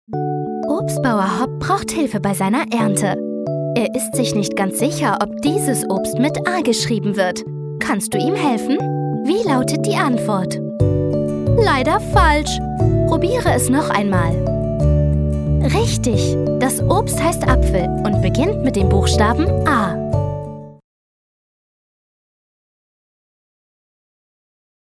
Eigenes Studio mit Schallkabine, Neumann-Mikrofon.
Kein Dialekt
Sprechprobe: eLearning (Muttersprache):